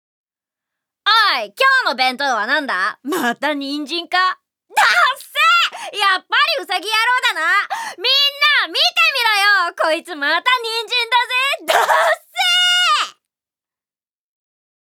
セリフ７